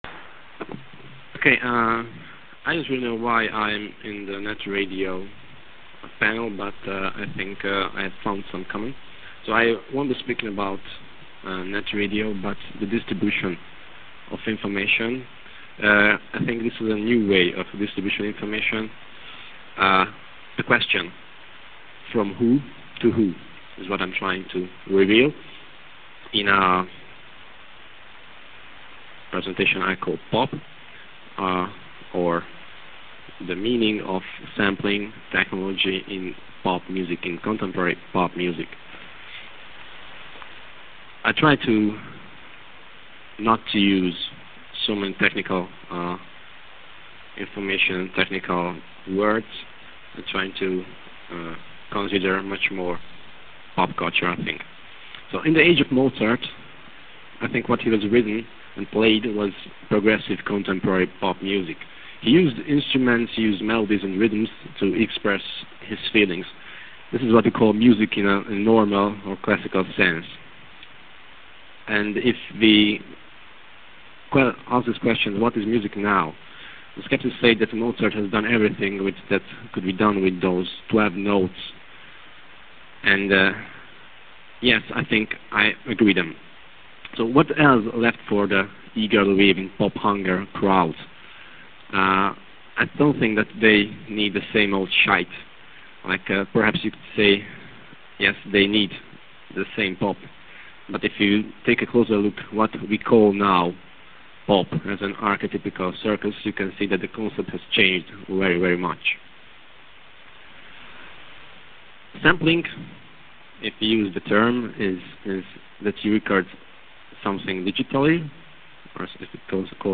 lectures from Xchange on air session (12-15 november 1997) | LNB Digitālā bibliotēka - DOM PIEEJA